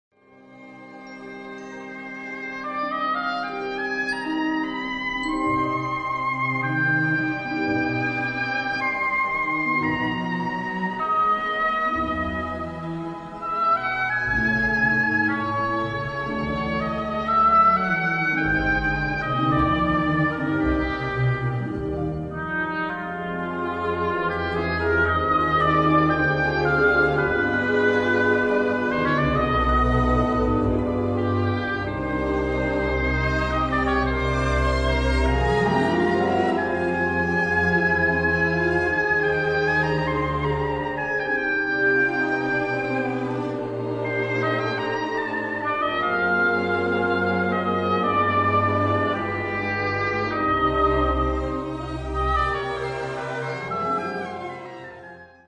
for Oboe & Orchestra